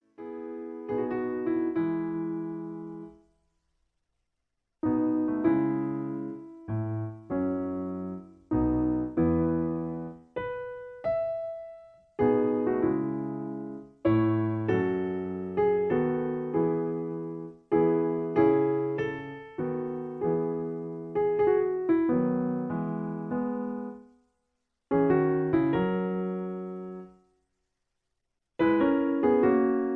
Original Key (E). Piano Accompaniment